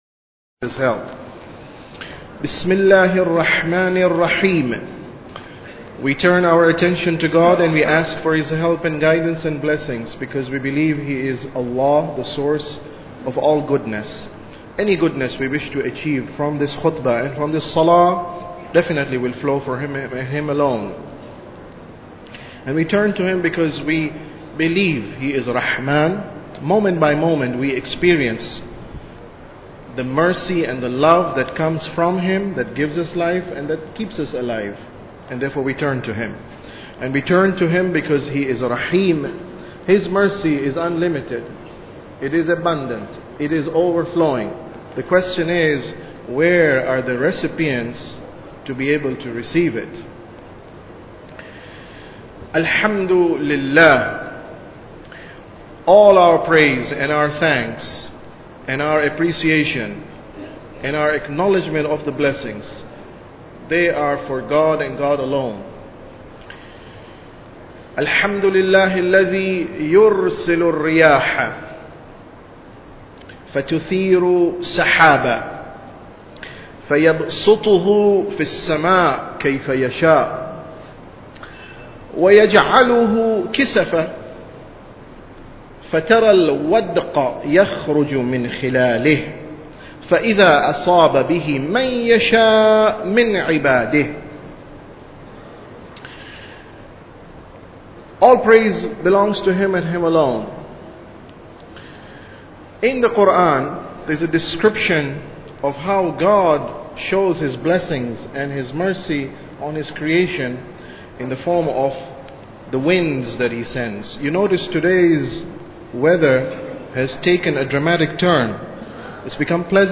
Sermon About Tawheed 14